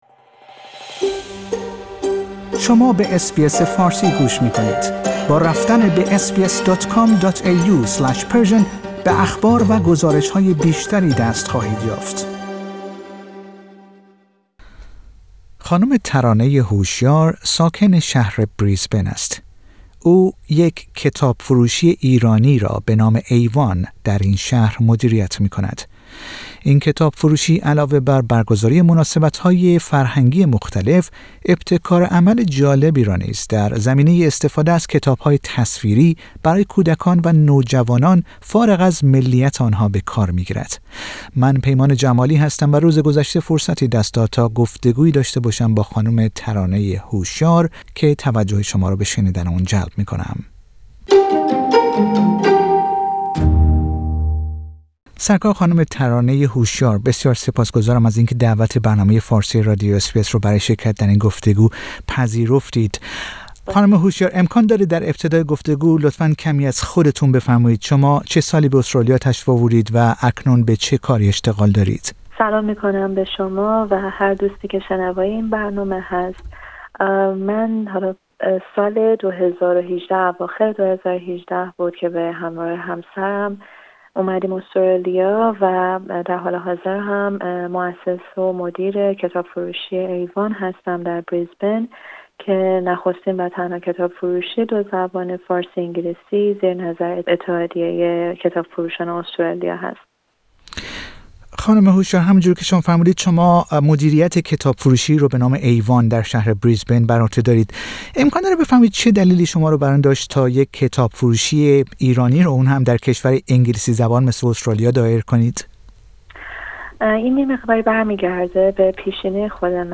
گفتگو با برنامه فارسی رادیو اس بی اس